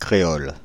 Ääntäminen
Ääntäminen France (Île-de-France): IPA: /kʁe.ɔl/ Paris: IPA: [kʁe.ɔl] Haettu sana löytyi näillä lähdekielillä: ranska Käännös Konteksti Ääninäyte Substantiivit 1. creole kielitiede UK US 2.